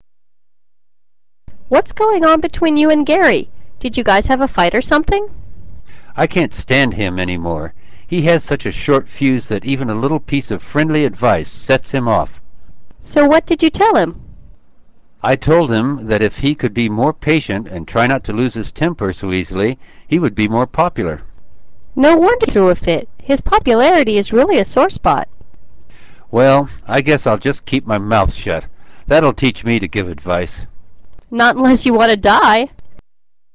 وتذكر أن سرعة المحادثة عادية.